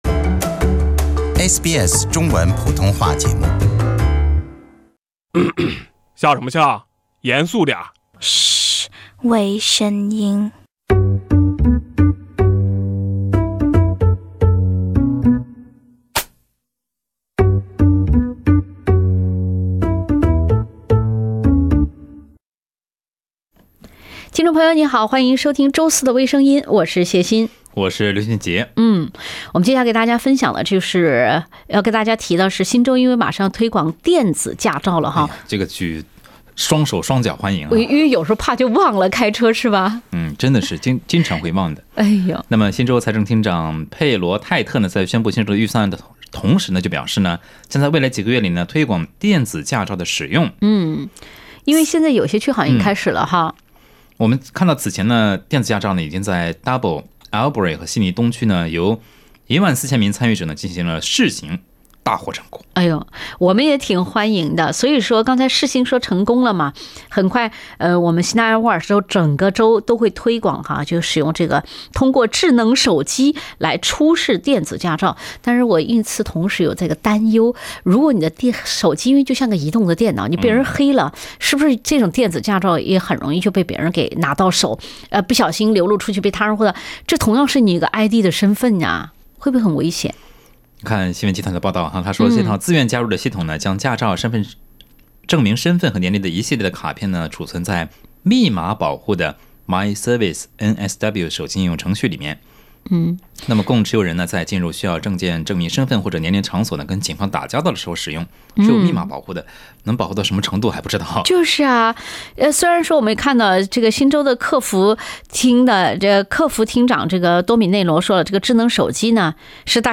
另类轻松的播报方式，深入浅出的辛辣点评，包罗万象的最新资讯，倾听全球微声音。